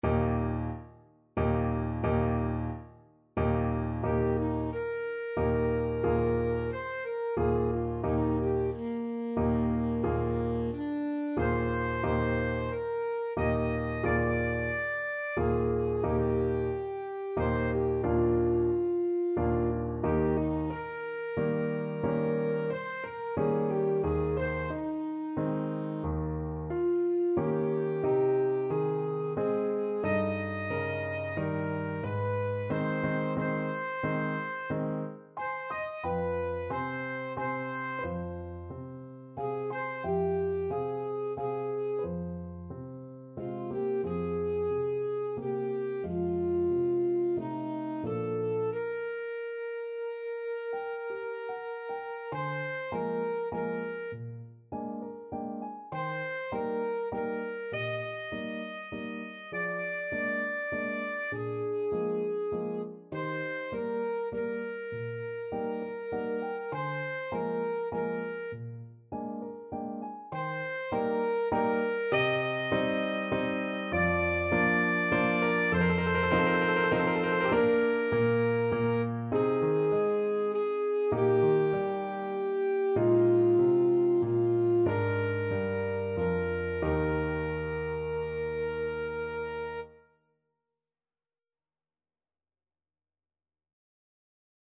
Alto Saxophone
3/4 (View more 3/4 Music)
~ = 90 Allegretto moderato
Eb major (Sounding Pitch) C major (Alto Saxophone in Eb) (View more Eb major Music for Saxophone )
Classical (View more Classical Saxophone Music)
strauss_fledermaus_bruderlein_ASAX.mp3